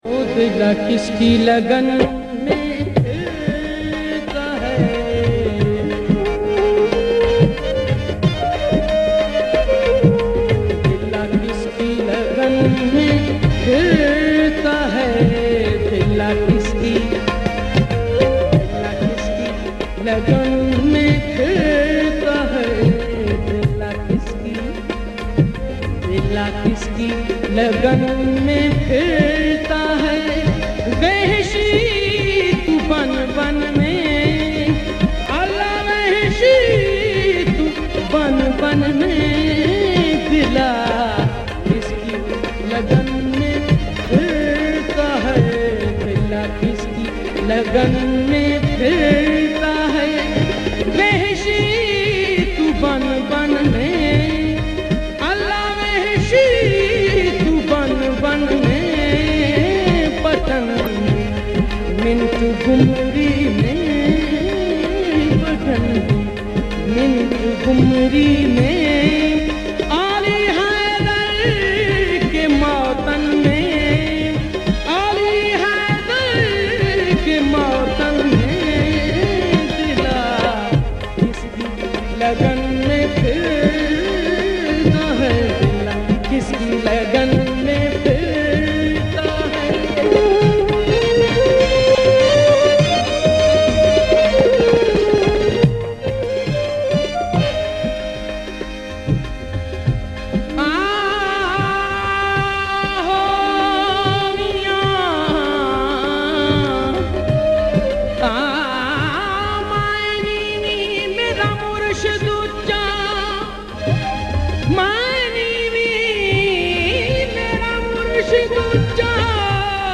Kalaam/Poetry ,ﺍﺭﺩﻭ Urdu
Arfana Kalam